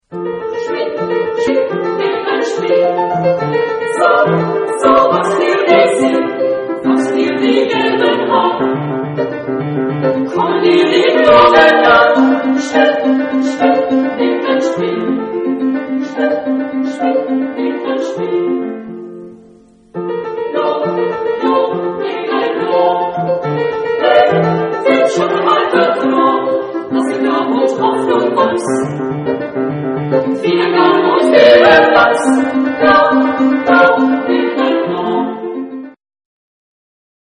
Genre-Style-Form: Lied ; Romantic ; Secular
Type of Choir: SSA  (3 women voices )
Instruments: Piano (1)
Tonality: F major